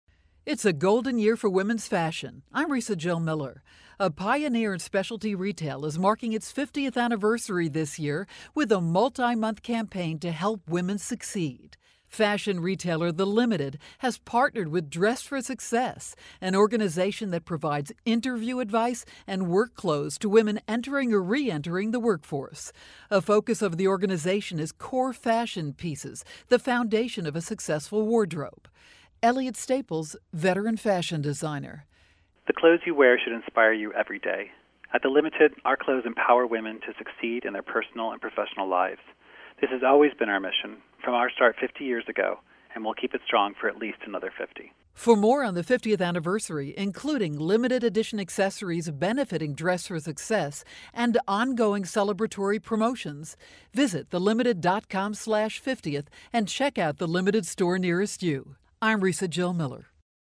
July 8, 2013Posted in: Audio News Release